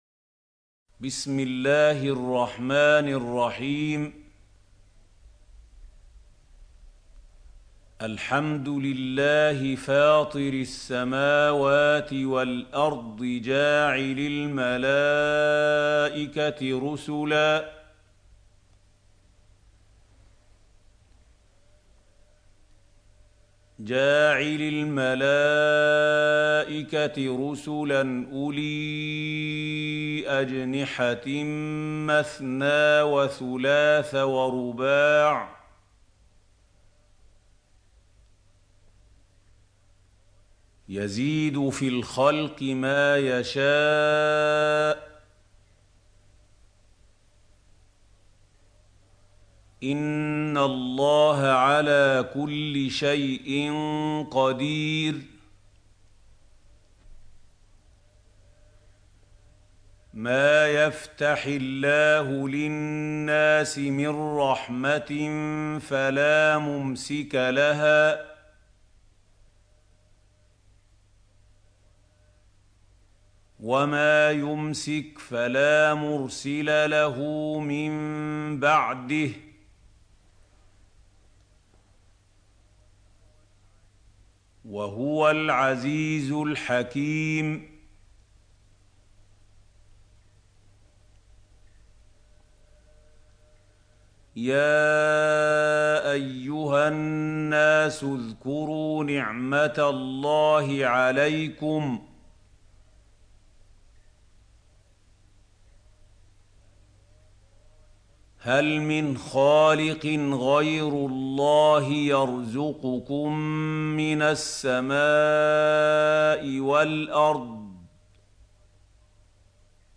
سورة فاطر | القارئ محمود خليل الحصري - المصحف المعلم